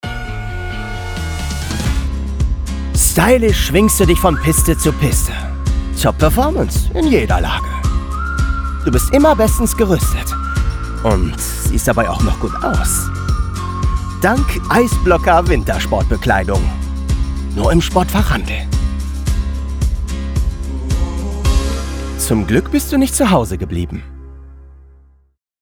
Ski and Winter Clothing Commercial
Young Adult
Acoustics: Vocal booth including Caruso Iso Bond 10cm, Basotect for acoustic quality.